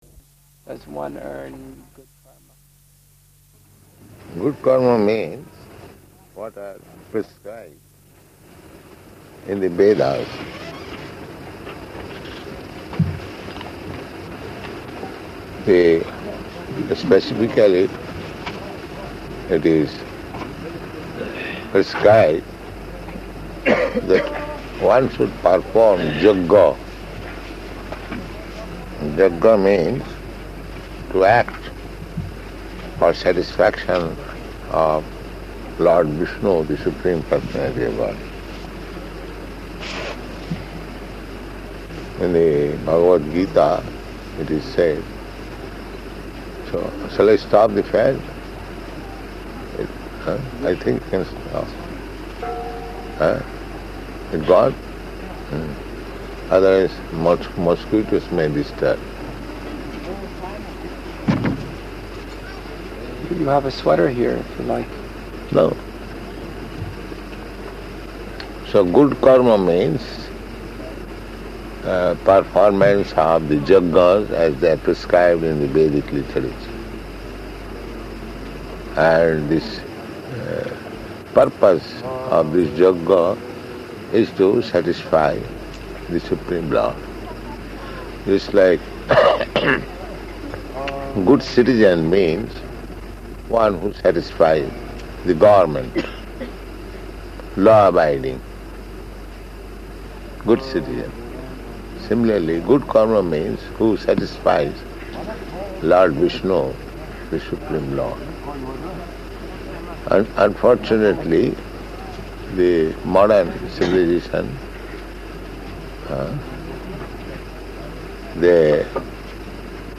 -- Type: Conversation Dated: February 29th 1972 Location: Māyāpur Audio file
[microphone movement sounds] ...some material happiness, and bad karma means you suffer from material distress.